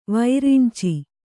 ♪ vairinci